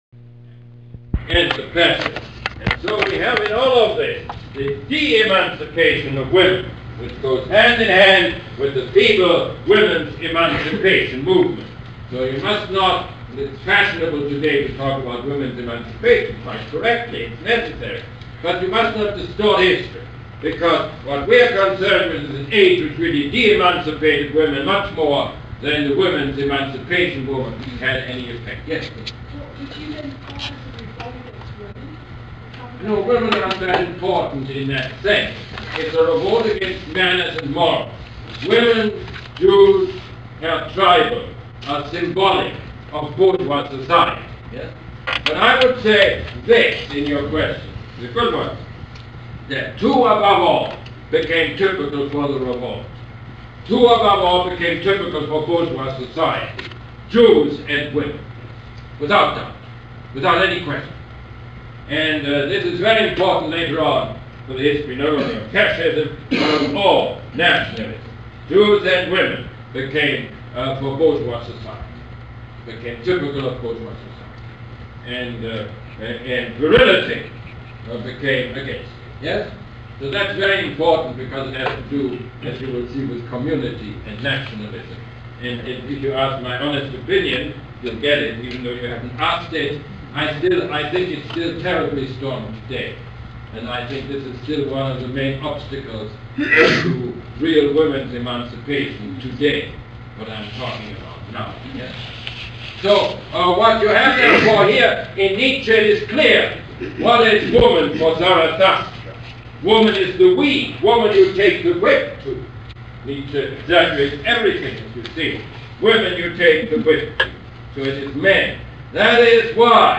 Lecture #7 - October 5, 1979